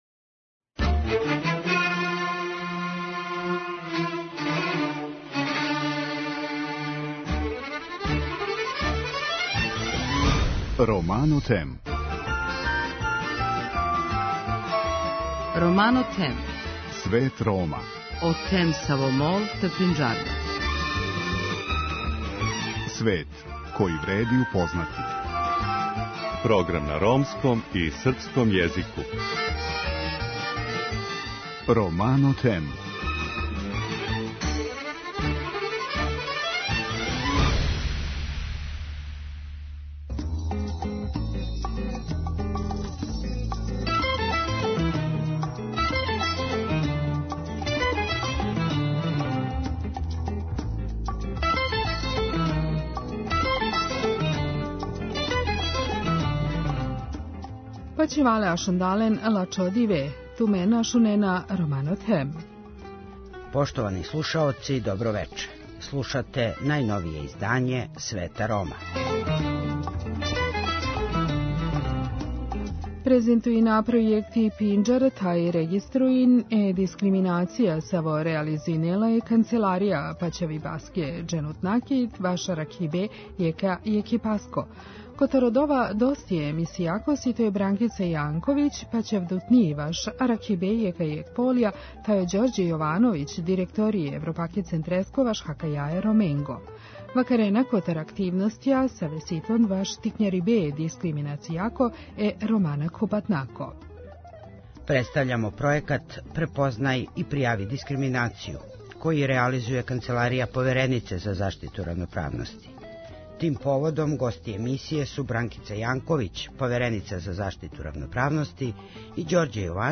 гости емисије су Бранкица Јанковић, повереница за заштиту равноправности